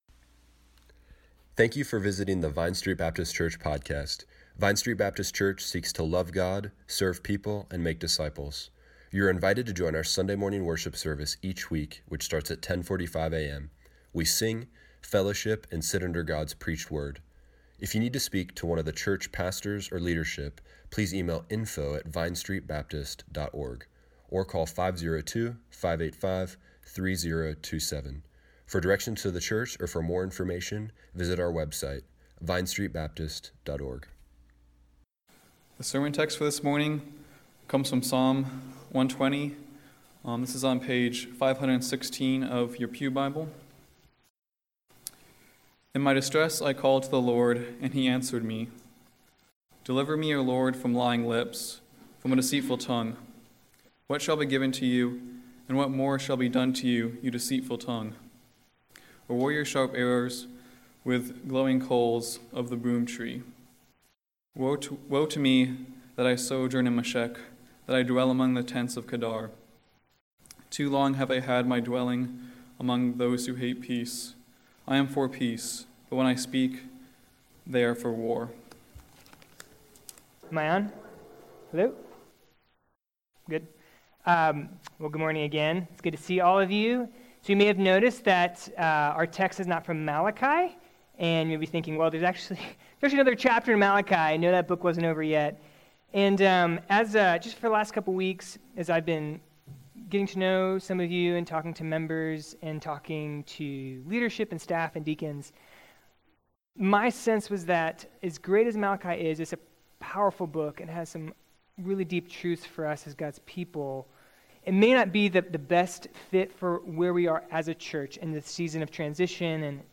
Service Morning Worship
2019 Theme: God hears us and he answers us, especially in our distress. Click here to listen to the sermon online.